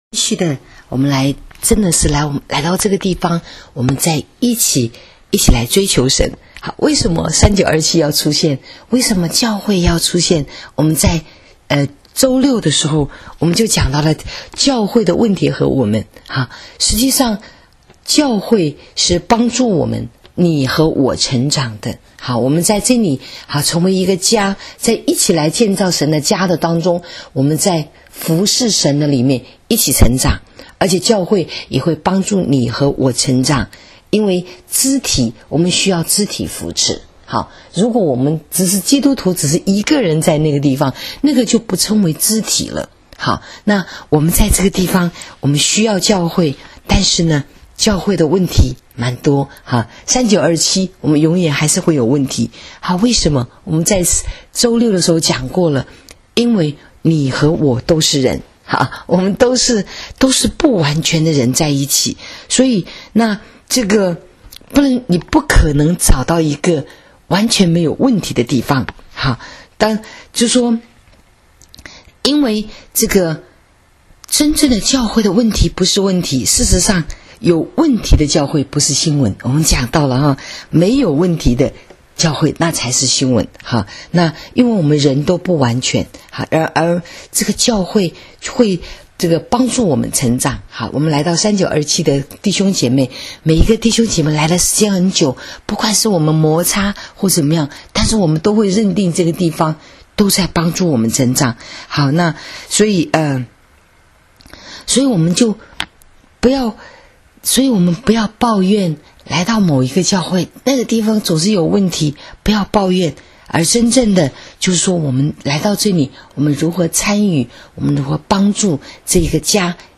【主日信息】教会问题与我们（2） （7-21-19）